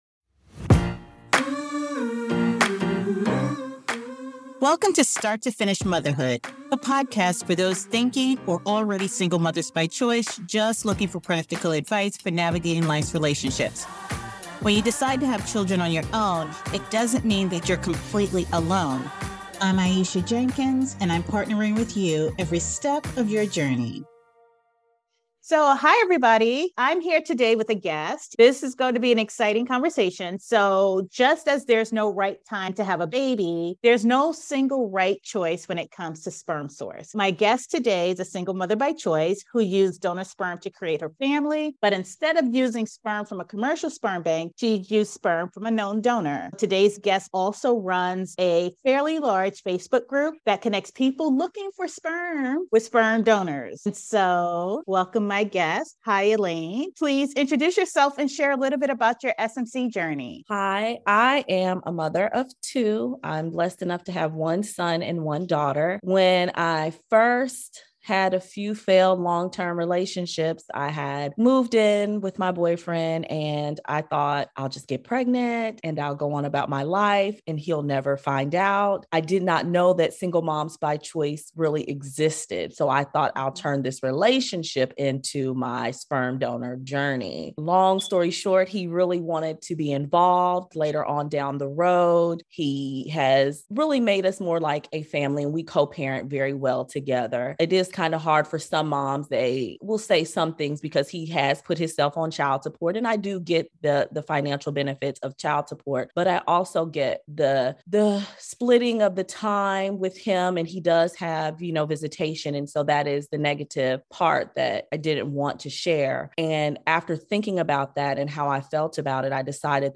Get ready for an honest and supportive conversation that challenges traditional norms and celebrates the power o